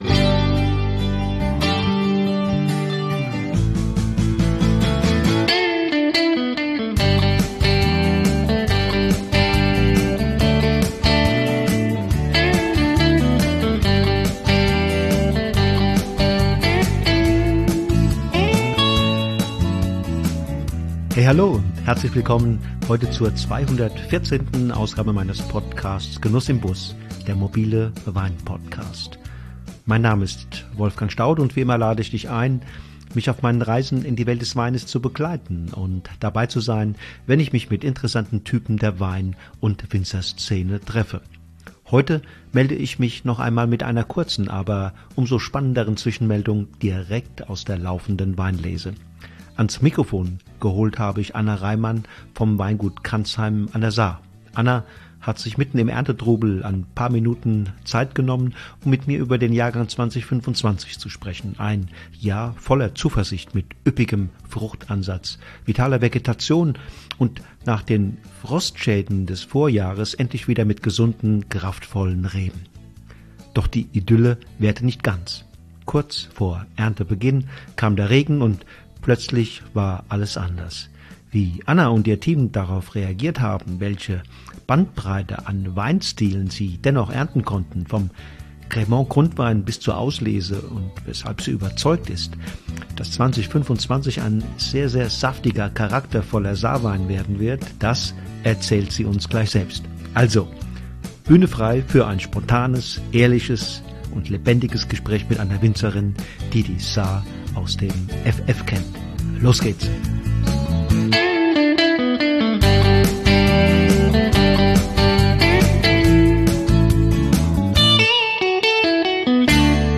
Ein spontanes, ehrliches Erntegespräch über Entscheidungen unter Zeitdruck, Fingerspitzengefühl im Weinberg und das Glück, endlich wieder volle Keller zu haben.